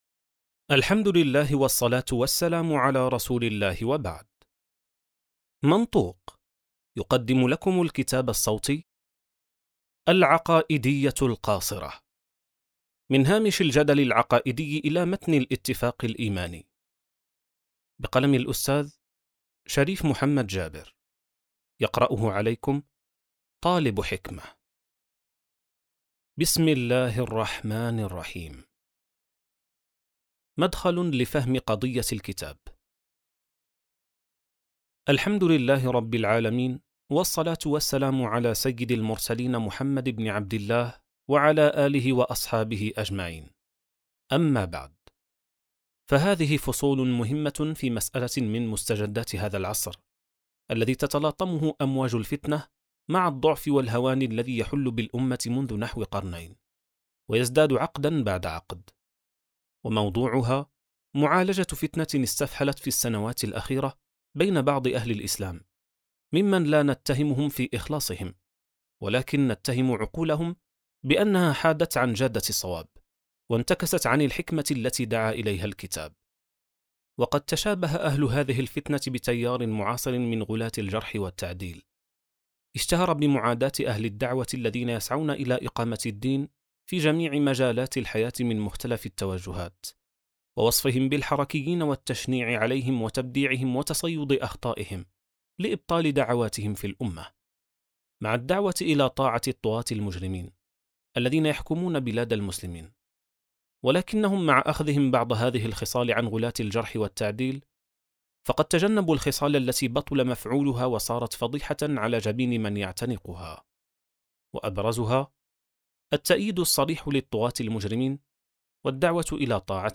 كتاب صوتي | العقائدية القاصرة (18): مدخل • السبيل